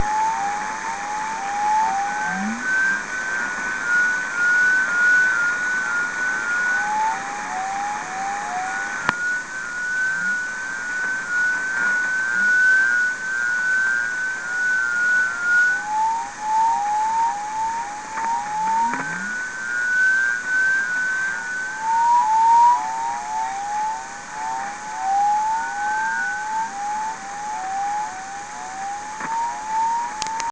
3633кГц 16.08.10 около 20:30-21:00, запись телефоном, как пример...